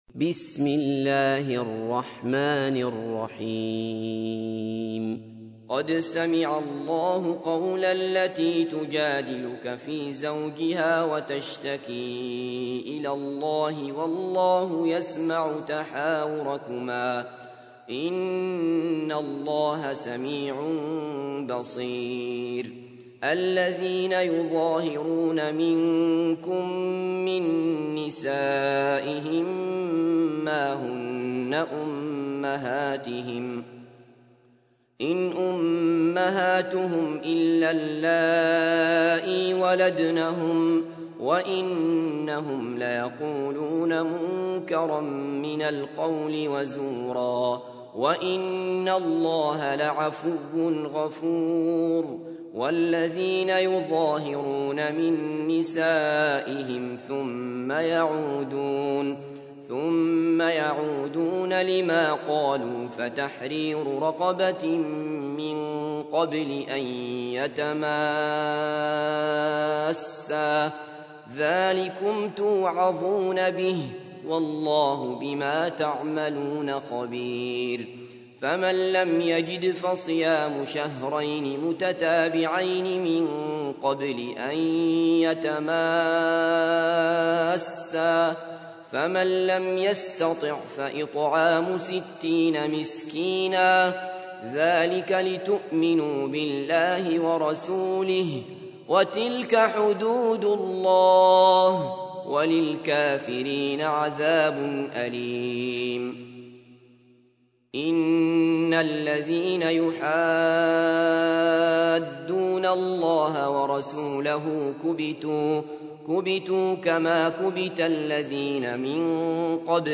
تحميل : 58. سورة المجادلة / القارئ عبد الله بصفر / القرآن الكريم / موقع يا حسين